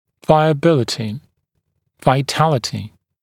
[ˌvaɪə’bɪlətɪ] [vaɪ’tælətɪ][ˌвайэ’билэти], [вай’тэлэти]жизнеспособность